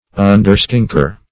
Underskinker \Un"der*skink`er\, n.